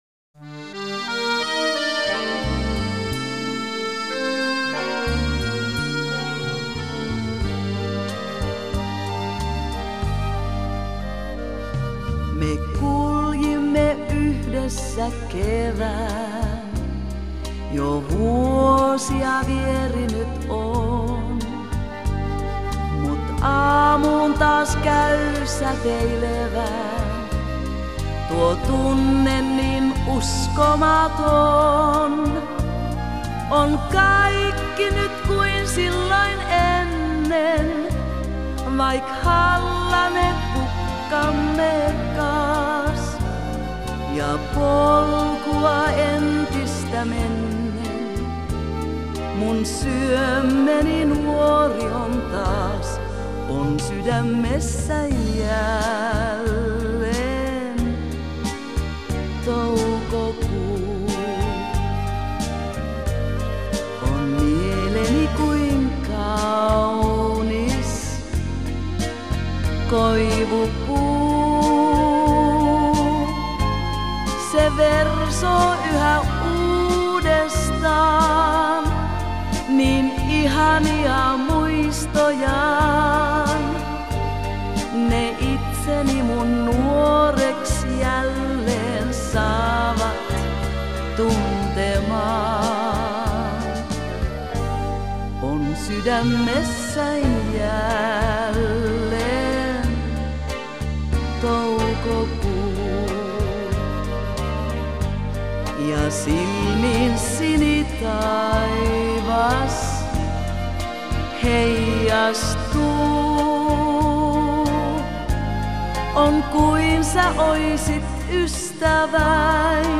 Жанр: Поп-музыка